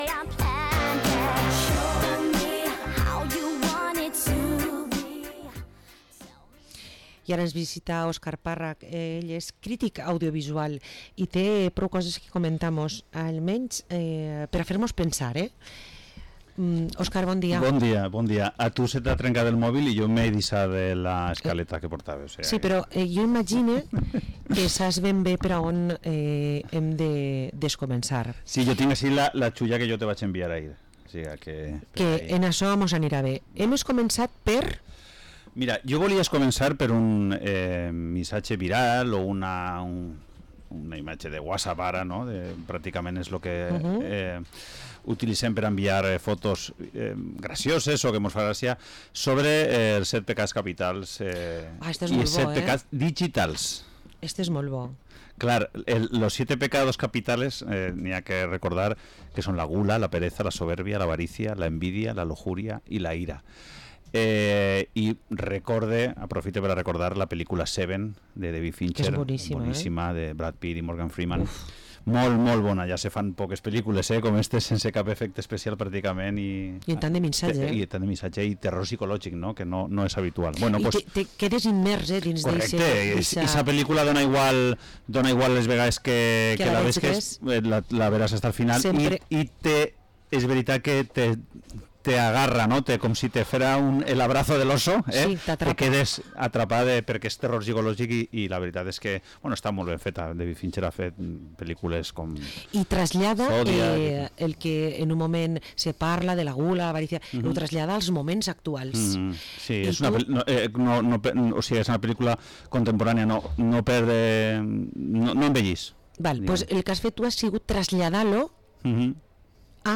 Entrevista al crítico audiovisual